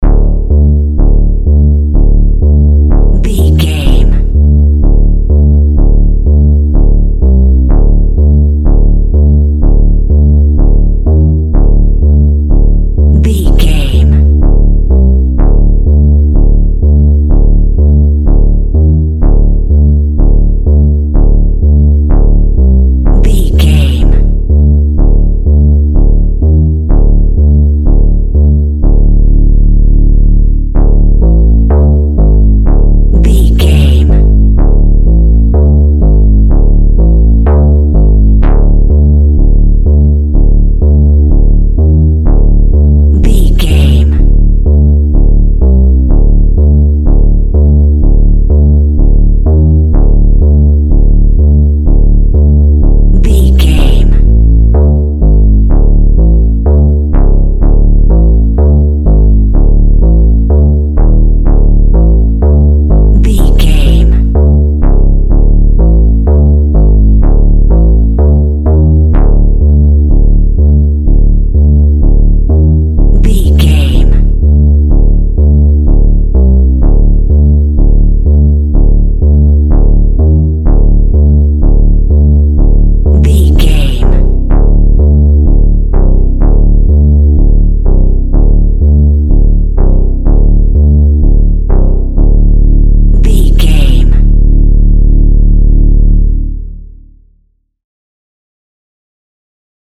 Aeolian/Minor
D
ominous
dark
suspense
eerie
Horror synth
Horror Ambience
synthesizer